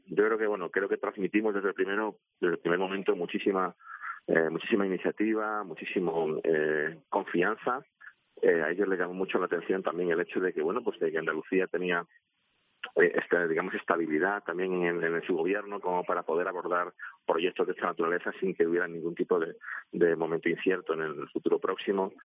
El consejero de Turismo, Cultura y Deporte, Arturo Bernal, ha contado en COPE Andalucía por qué la Academia ha accedido a celebrar la gala fuera de Estados Unidos por primera vez